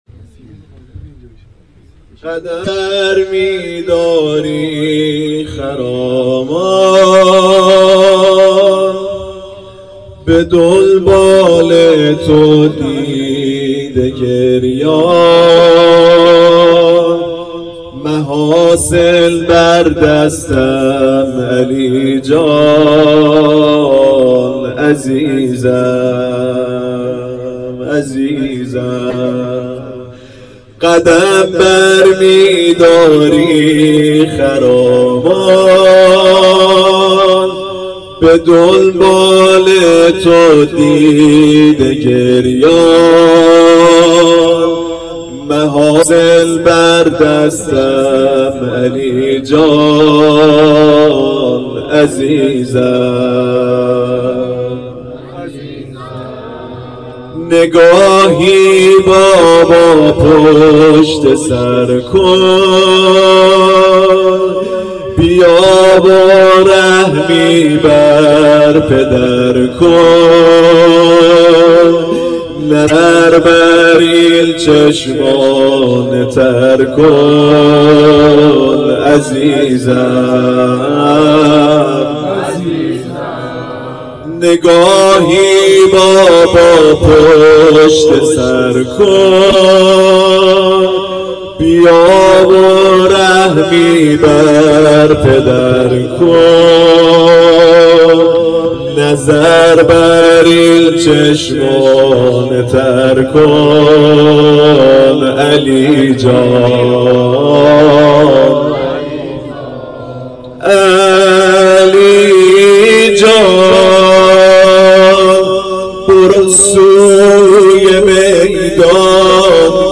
5-nohe.mp3